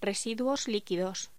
Locución: Residuos líquidos
Sonidos: Voz humana